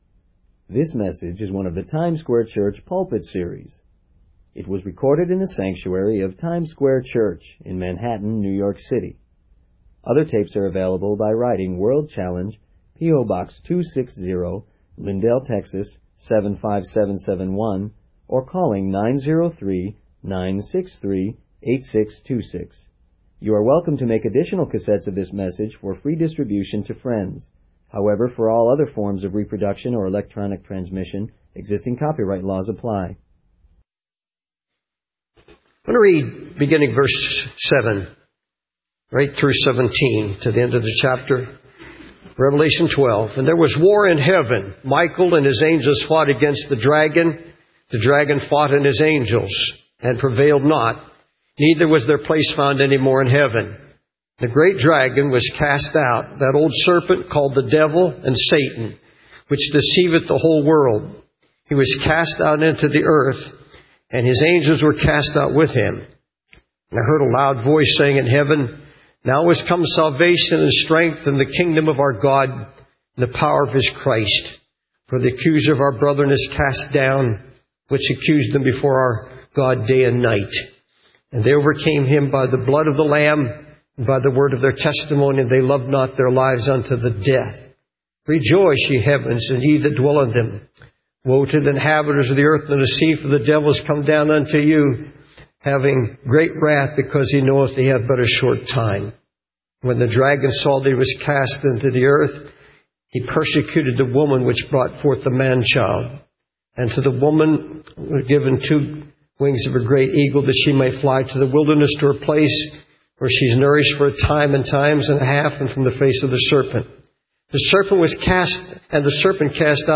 In this sermon, the preacher emphasizes the importance of being ready for the return of Jesus Christ. He uses the analogy of a house on fire to illustrate the urgency of the situation.